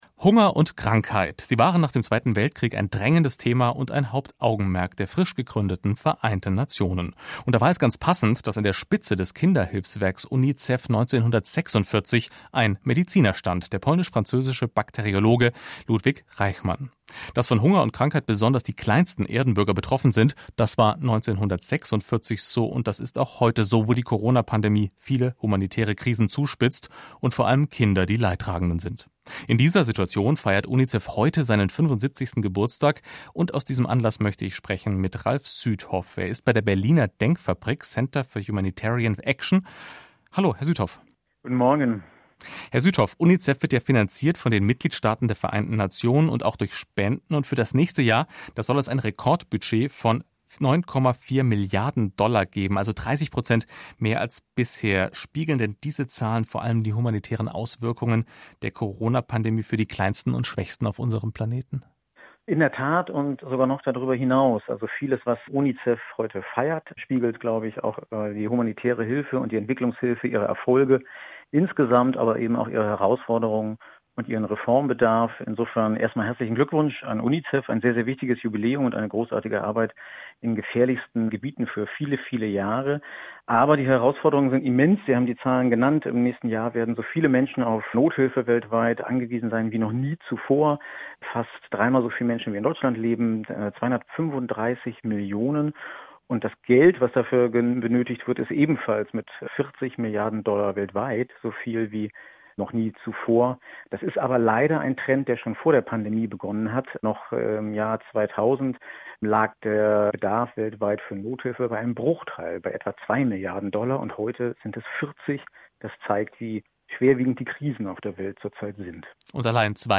Mitschnitt der Radiobeitrags: